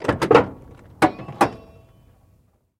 Cadillac 1964 Door Open, Rusty, Spring Noises